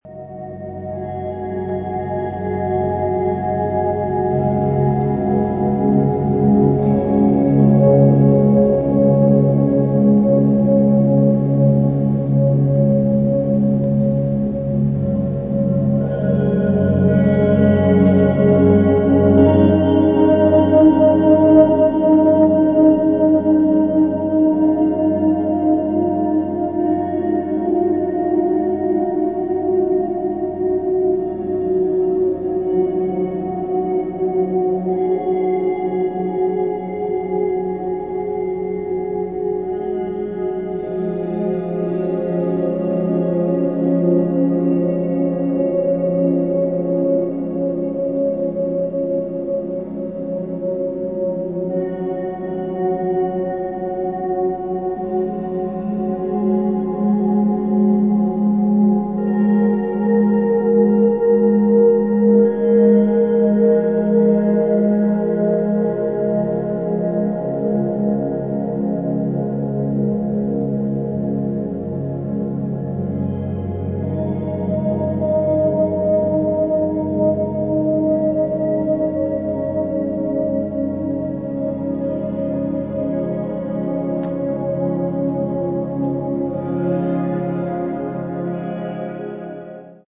沈黙の中に響く微かなノイズ。
ギターの透明な響きは美しい終わりの光景へと溶けていきます。